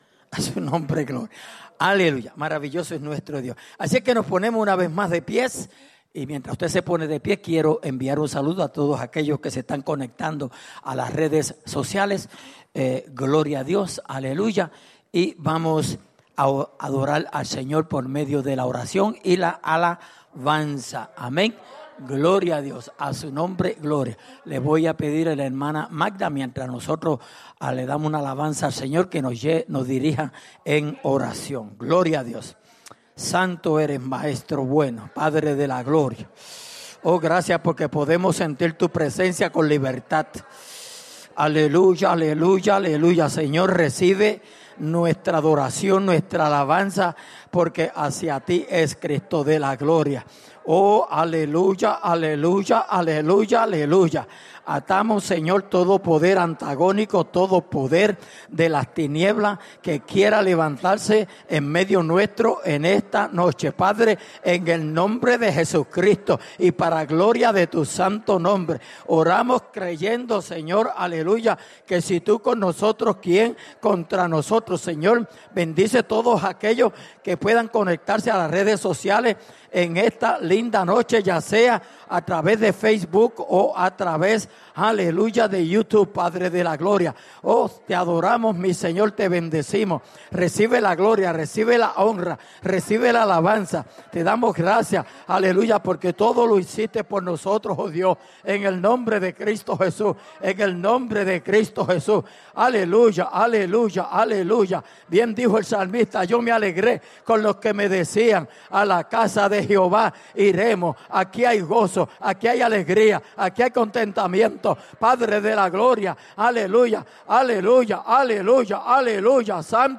en la Iglesia Misión Evangélica en Souderton, PA